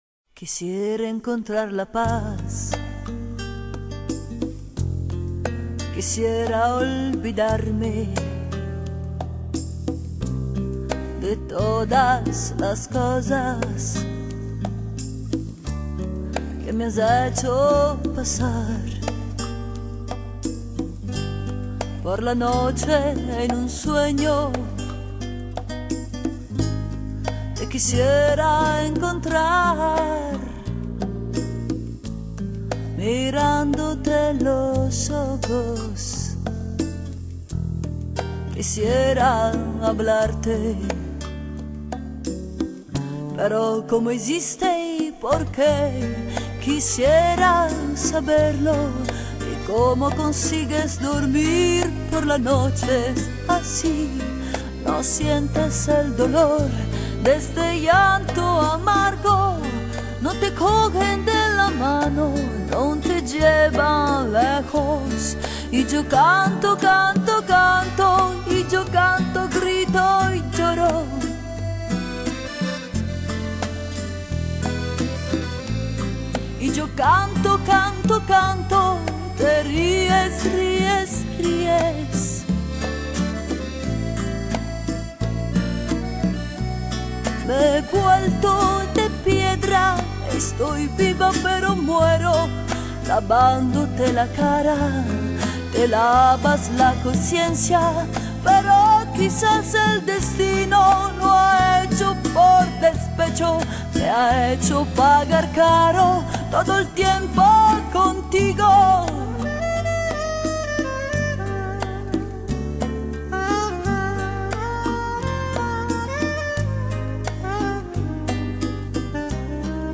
Il sito ufficiale della cantante partenopea.
Classici napoletani più due inediti cantati in spagnolo.